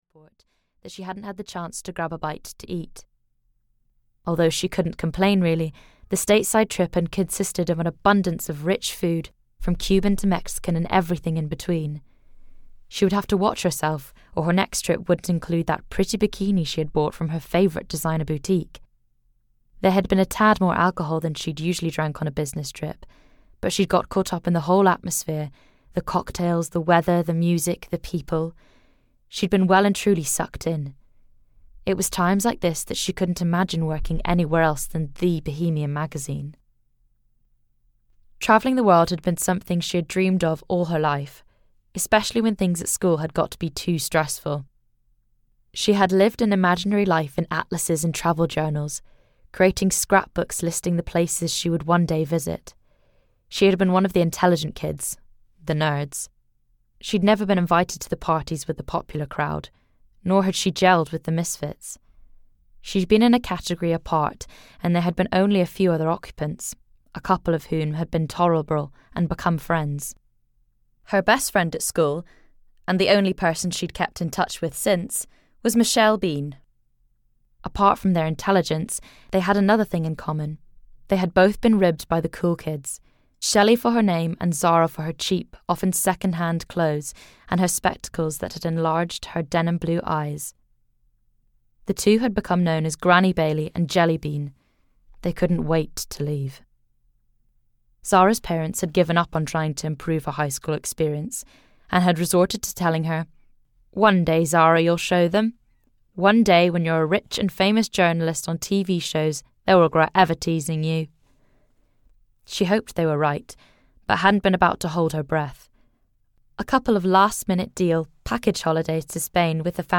A Summer of New Beginnings (EN) audiokniha
Ukázka z knihy